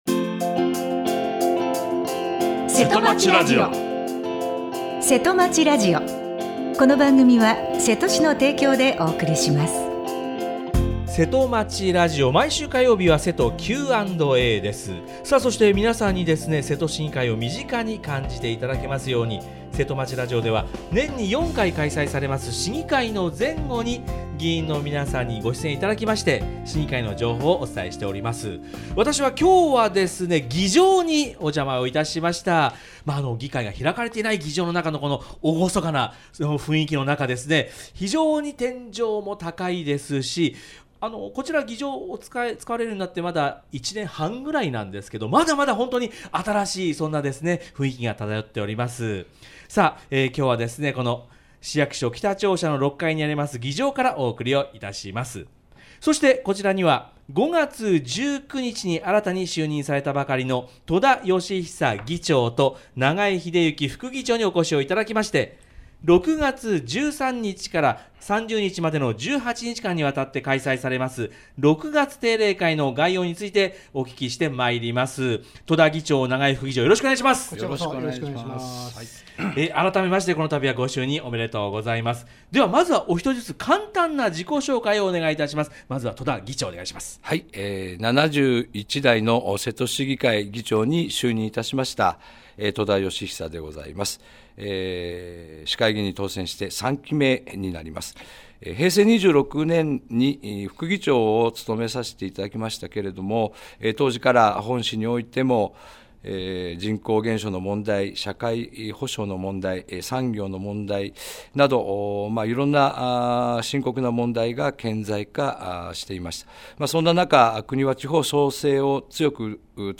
28年6月14日（火） 毎週火曜日は”せとおしえてQ&A” 今日は現場レポートです。